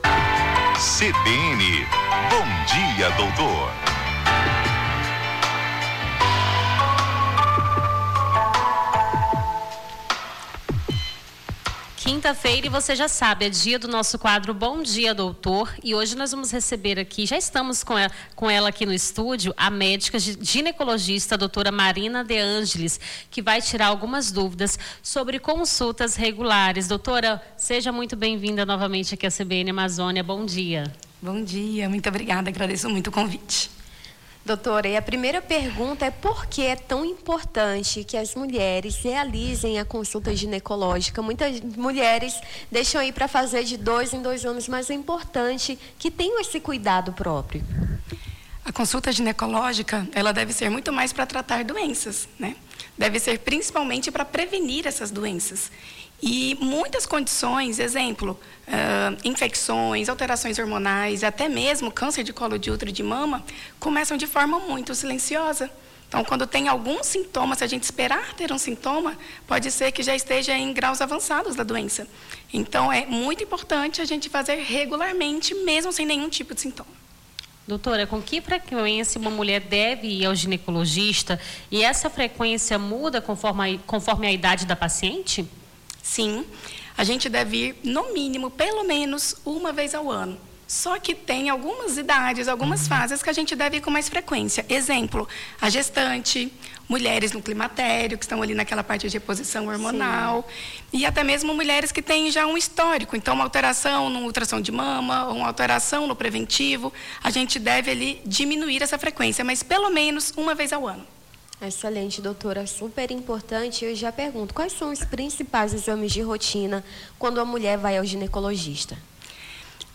Bom dia, doutor: ginecologista esclarece dúvidas dos ouvintes
O Jornal da Manhã conversou com a médica